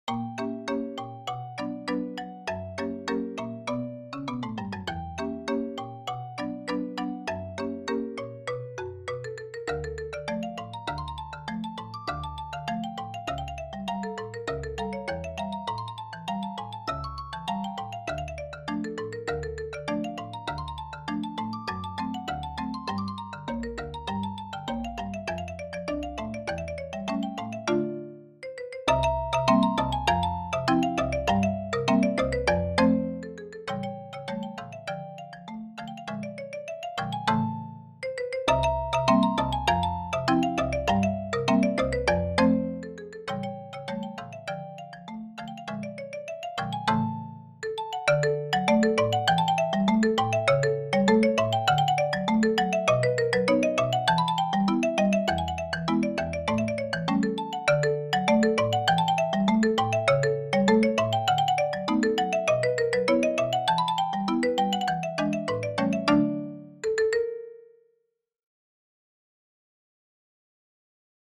Voicing: Marimba Quartet